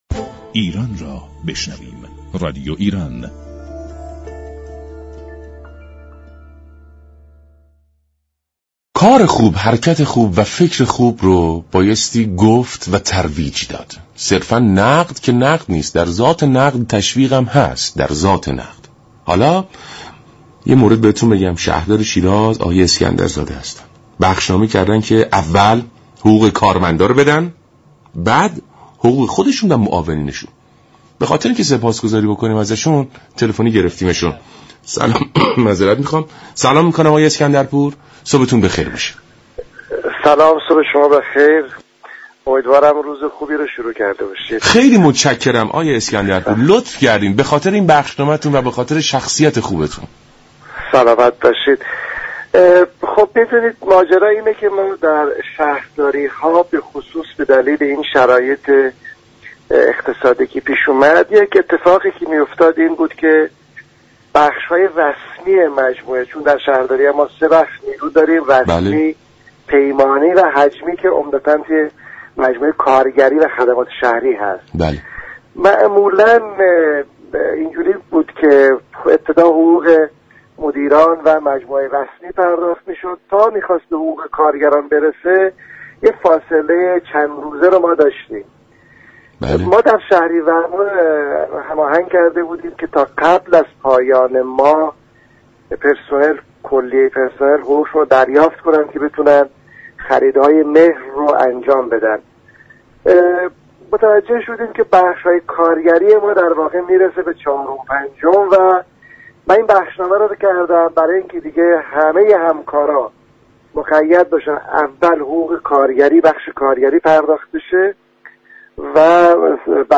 شهردار شیراز در گفت و گو با رادیو ایران گفت